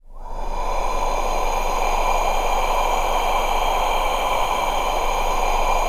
O-NOISEBED.wav